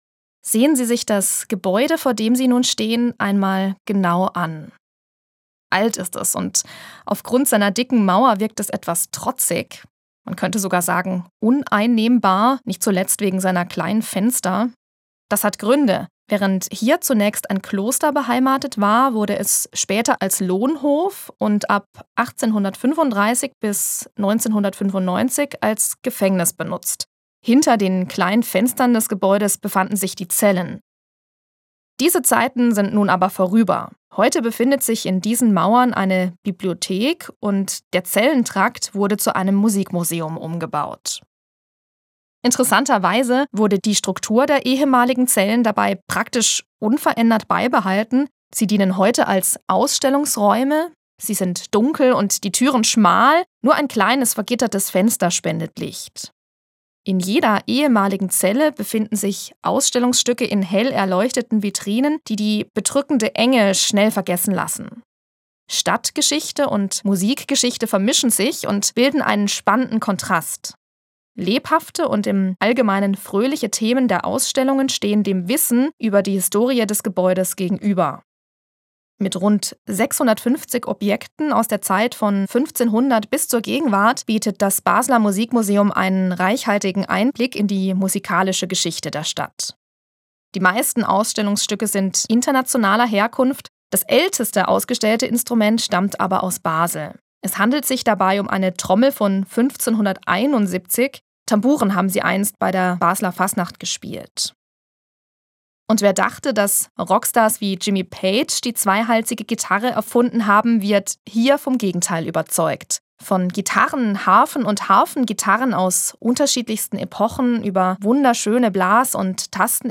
Scarborough fair für Harfengitarre) (2017):